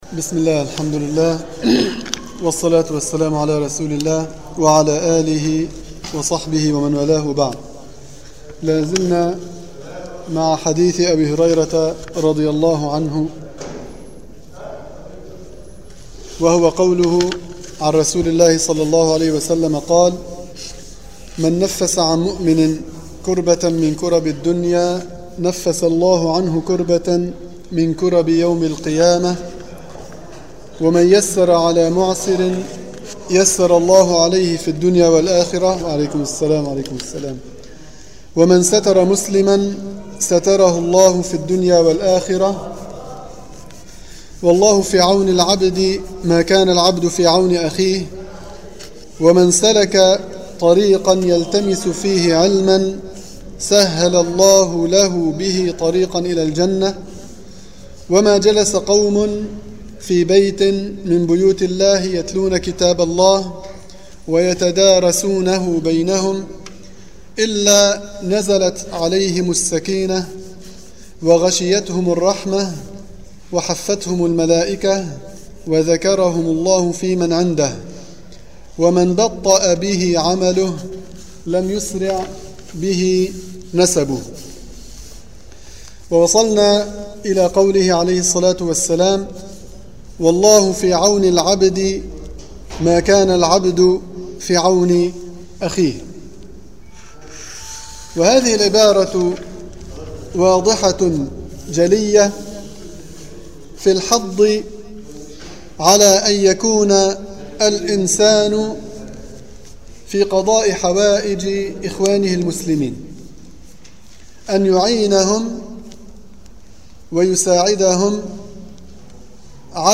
دروس تفسير الحديث
المكان: مسجد القلمون الغربي